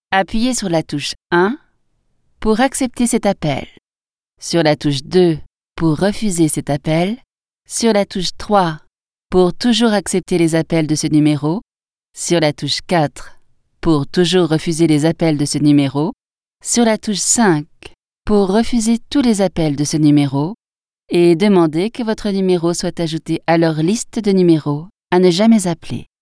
Professional Voice Prompts in French